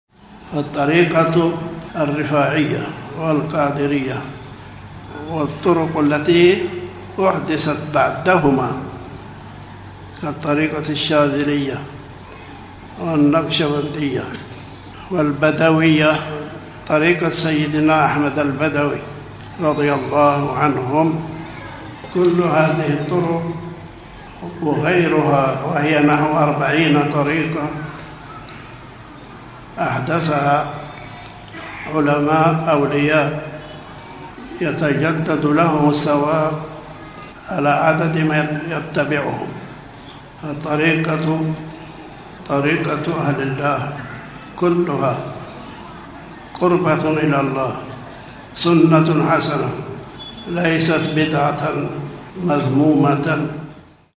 من نصائح العلامة المحدث الشيخ عبد الله الهرري